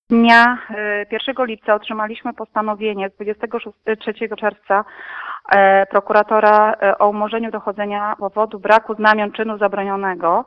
Taką decyzję podjął prokurator Daniel Depko z Prokuratury Rejonowej w Pyrzycach – wyjaśnia Marzena Podzińska, burmistrz Pyrzyc.